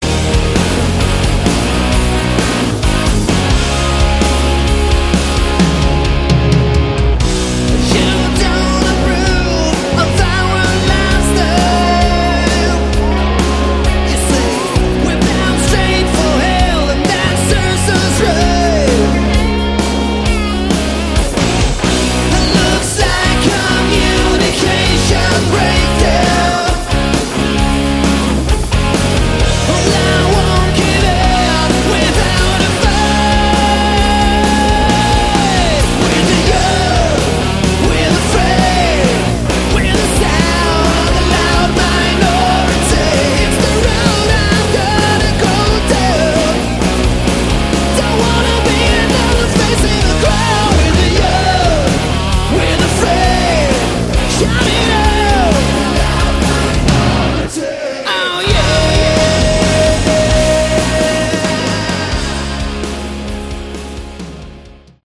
Category: Sleaze Glam
vocals
guitar
drums
bass